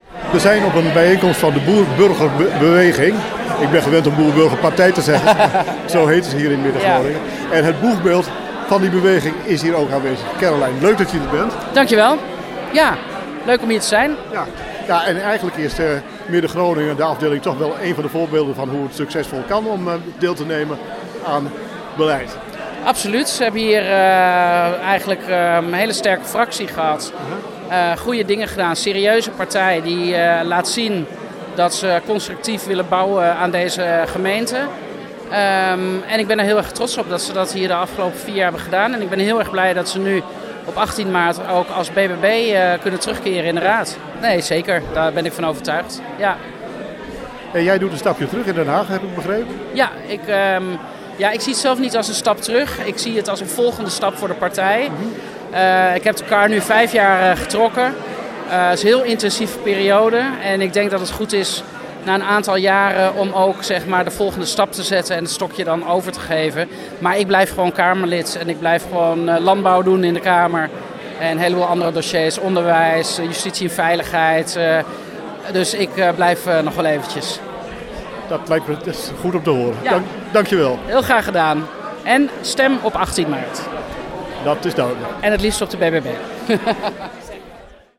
Bijeenkomst BBB Zuidbroek 28 feb. Caroline van der Plas.
Interview met Caroline van der Plas: